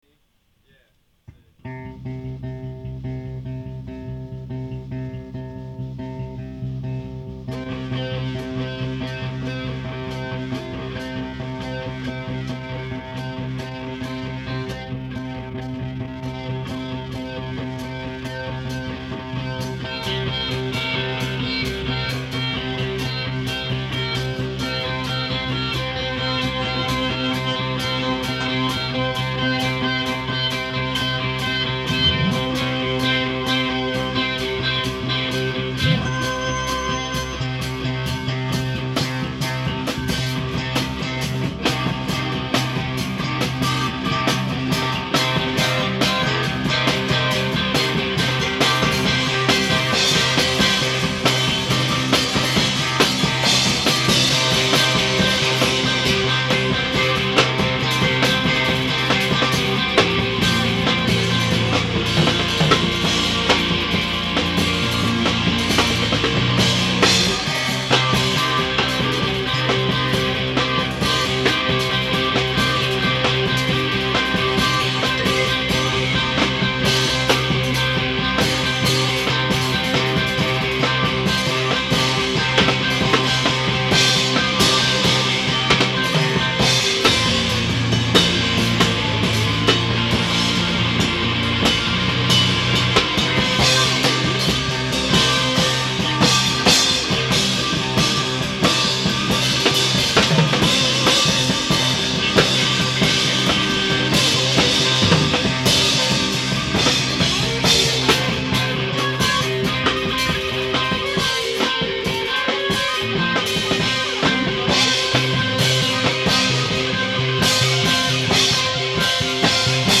Curious Yellow, My High School Rock Band
a rhythmic experiment